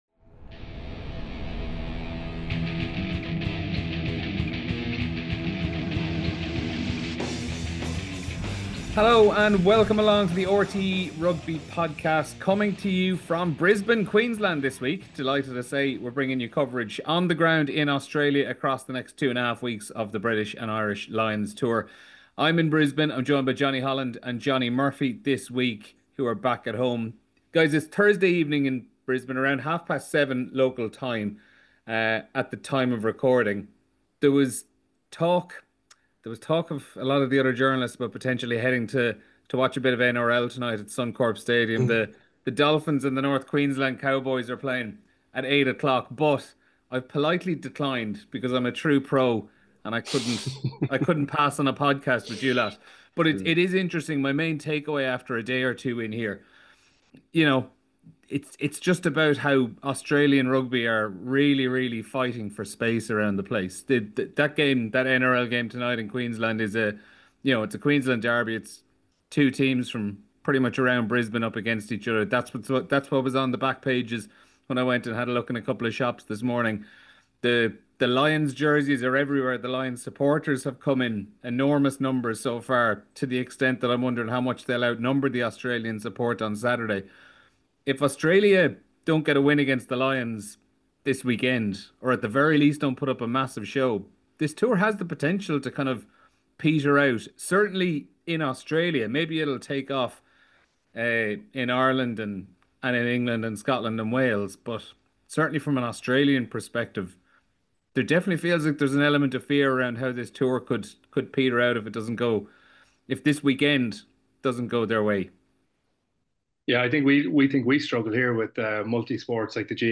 1 Better all round, well apart for Arg. 45:36 Play Pause 5h ago 45:36 Play Pause Play later Play later Lists Like Liked 45:36 In this weeks podcast the boys talk over breaking news from all over the world. They cast their eye over this weekends many matches and answer a fantastic listeners questions.